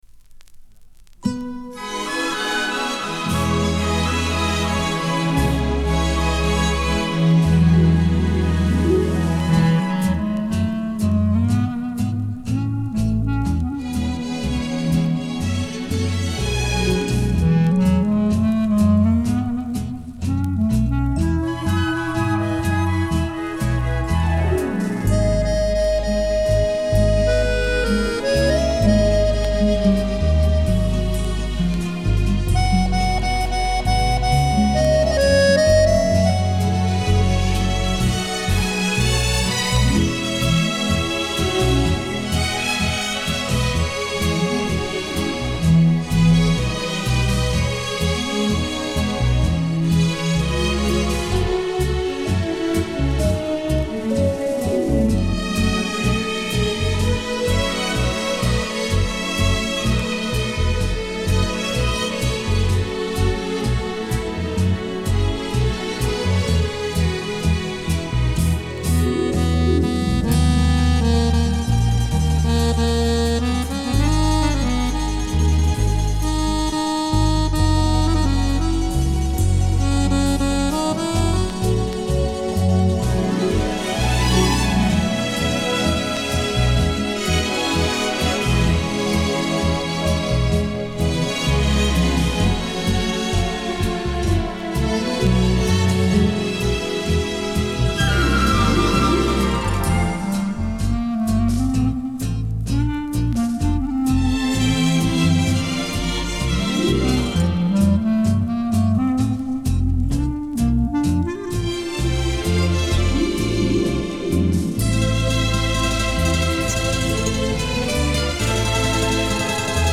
Genre:Latin
Style:Tango, Bolero